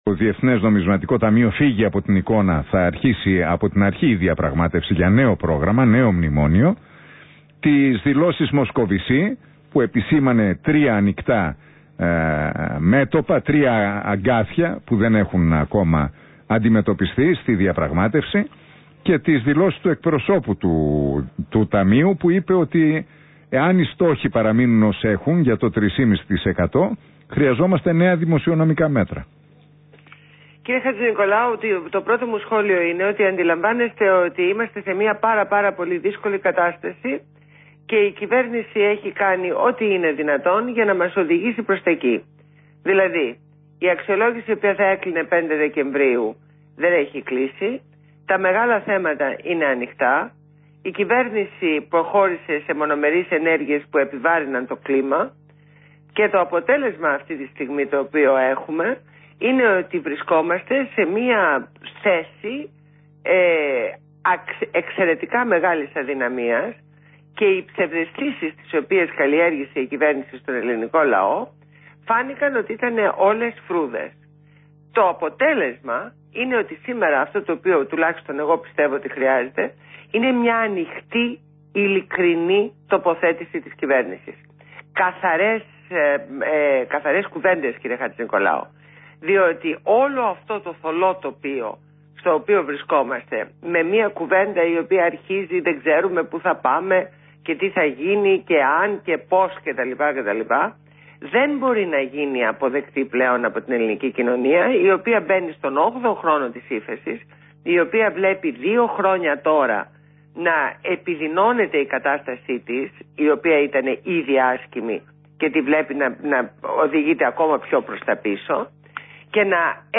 Συνέντευξη στο ραδιόφωνο REALfm στο δημοσιογράφο Ν. Χατζηνικολάου.